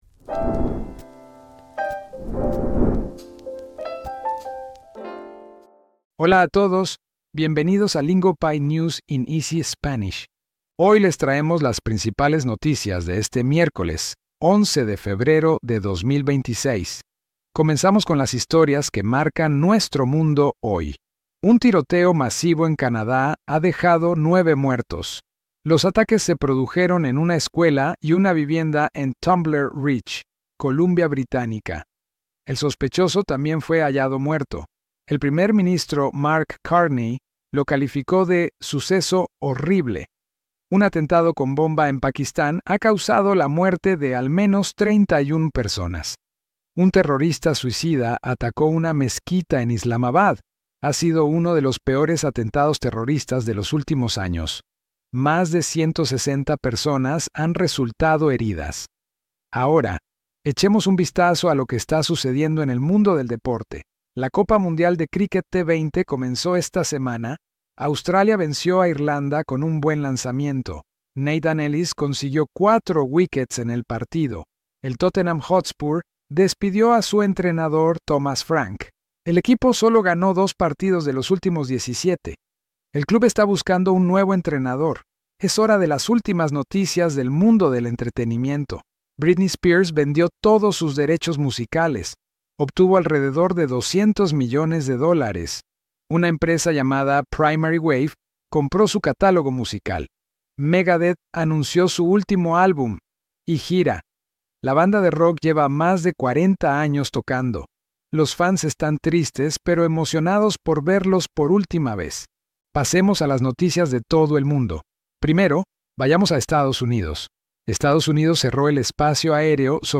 Lingopie’s News in Easy Spanish turns global headlines into real Spanish listening practice. We tell each story in clear, beginner-friendly Spanish at a steady pace, so you can follow along without the “hold on… what did they just say?” moment.